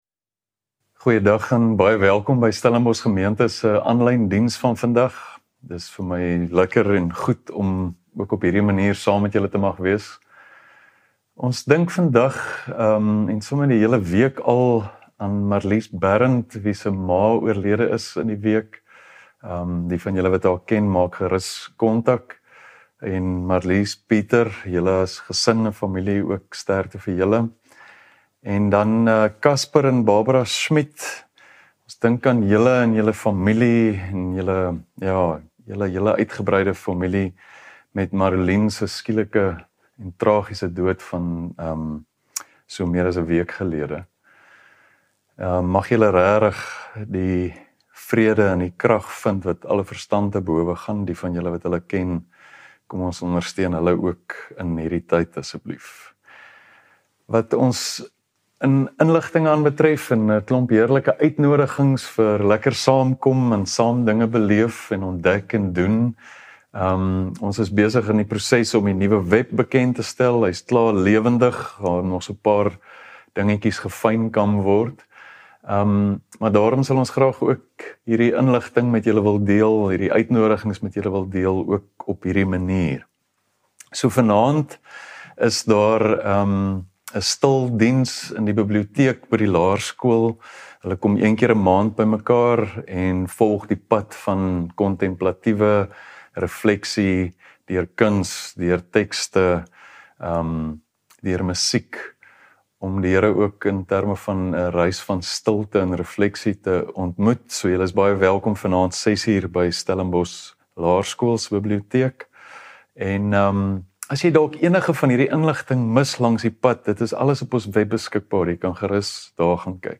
Stellenbosch Gemeente Preke